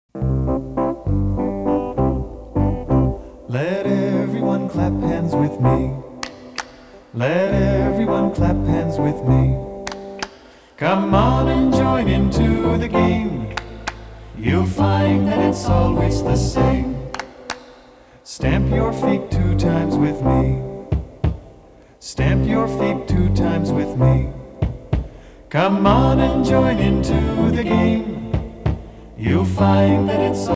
Home > Folk Songs